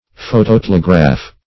-- Pho`to*tel"e*graph , n. -- Pho`to*tel`e*graph"ic , a. [Webster 1913 Suppl.]
phototelegraph.mp3